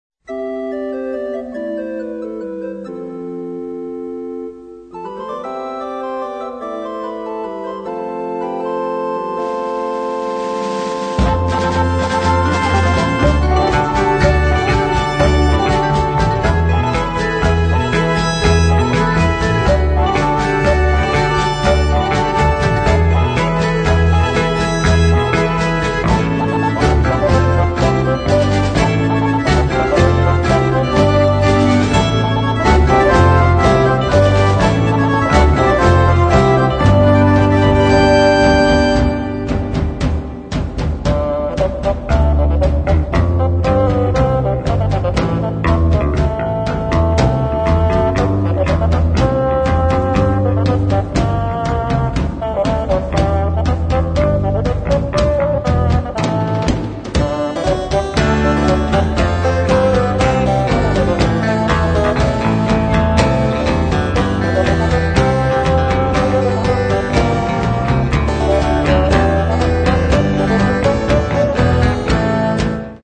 Stereo, 1:13, 56 Khz, (file size: 500 Kb).